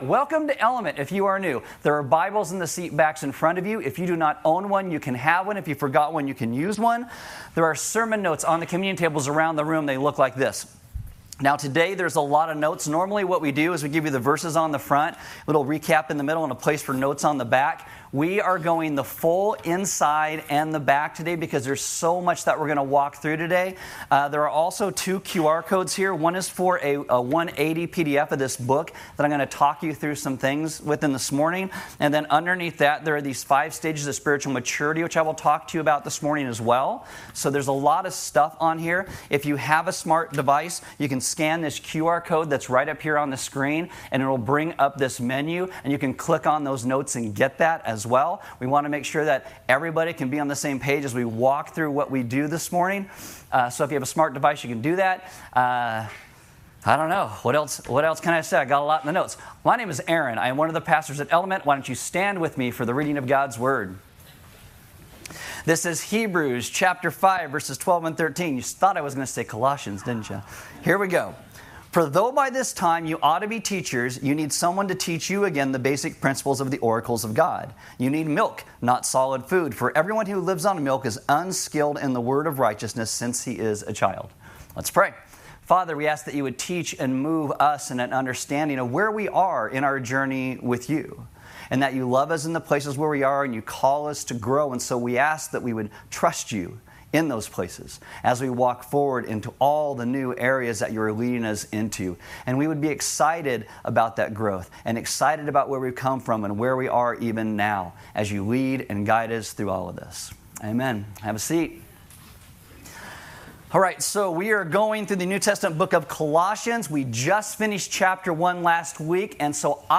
NOTE: We are still working on importing our vast sermon library.